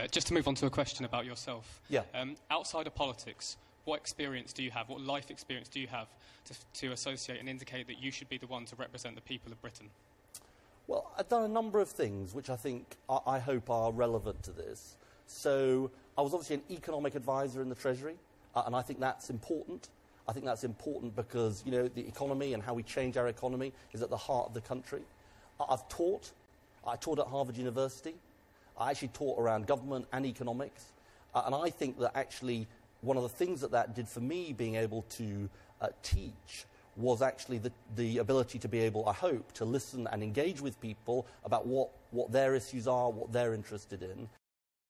Ed Miliband discusses his qualifications for being PM
From Sky News, 2 February 2015